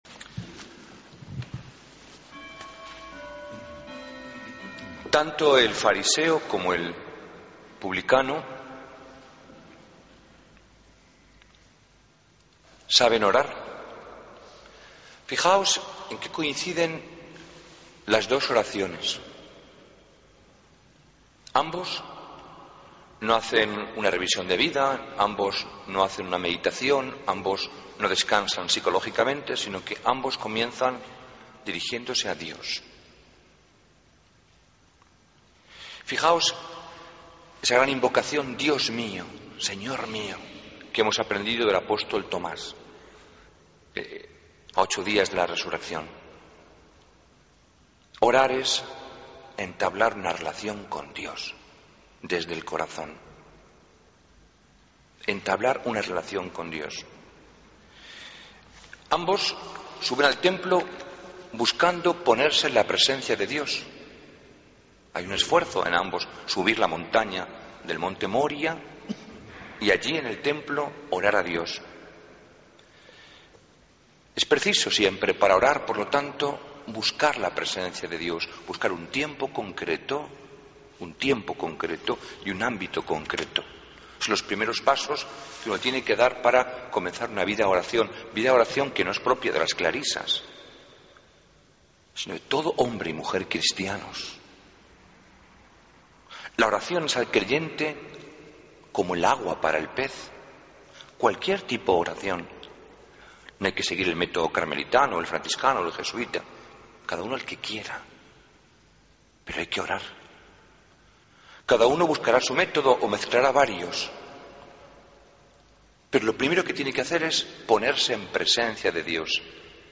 Homilía del 27 de Octubre de 2013